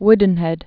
(wdn-hĕd)